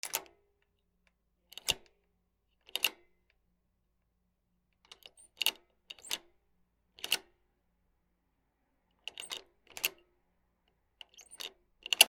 鍵 『カチャ』
/ K｜フォーリー(開閉) / K35 ｜鍵(カギ)